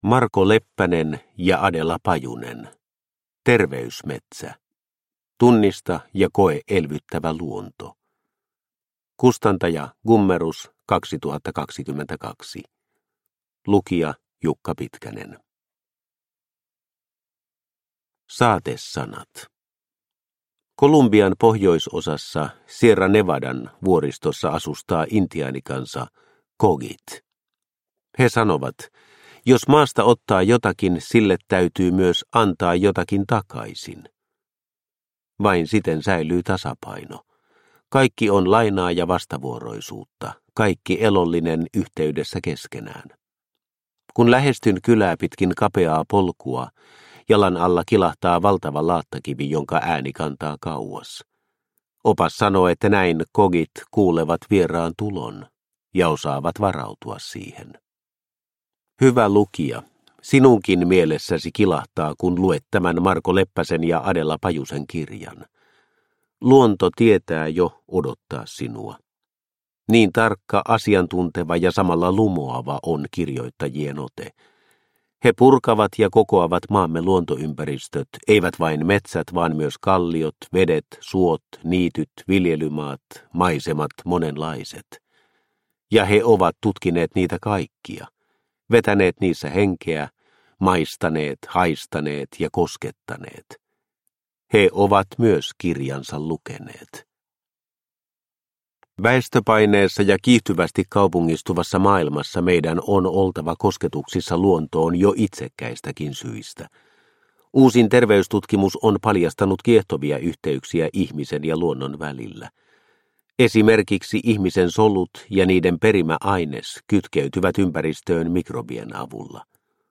Terveysmetsä – Ljudbok – Laddas ner